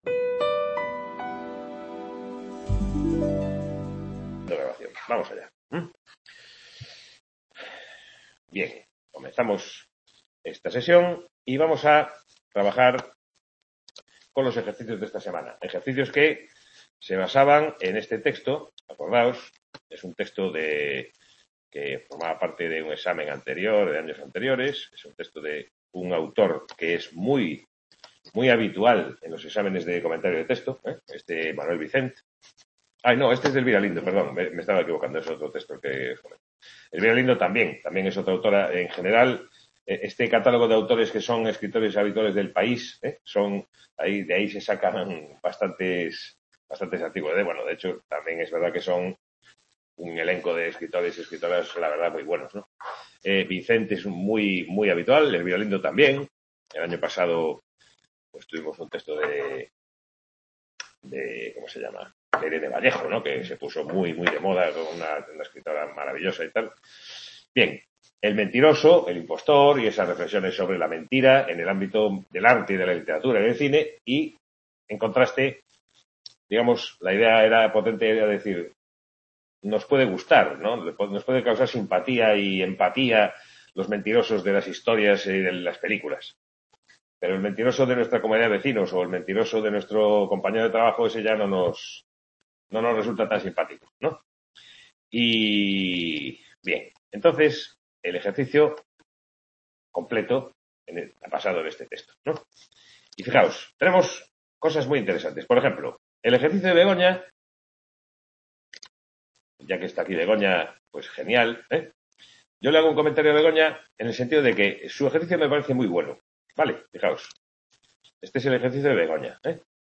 Modelo de examen. Comentario de texto. Aula de Vigo. Sesión de tutoría (23/03/2022) Description Sesión de tutoría de la asignatura Comentario de Texto, centrada en la revisión de aspectos básicos para la realización del examen de la asignatura.